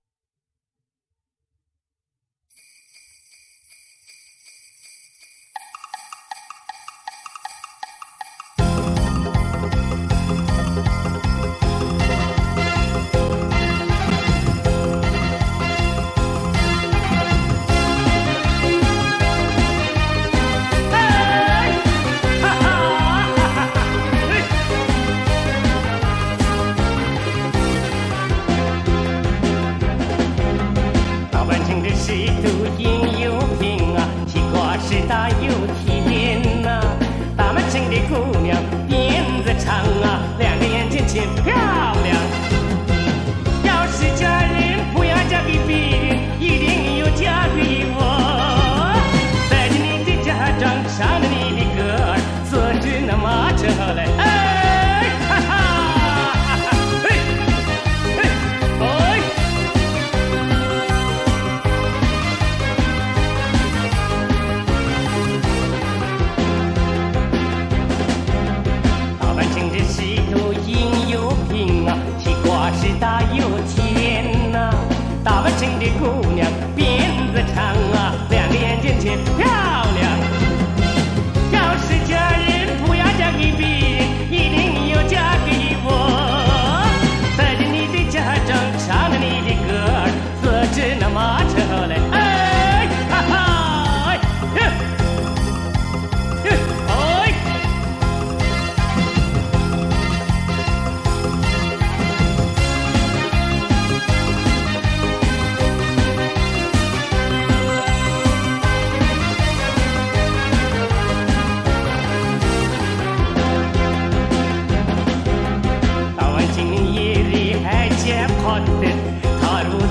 这个系列的磁带制作精良，采用了国内磁带很少使用的杜比B NR及HX PRO技术，音响效果非常不错。
磁带数字化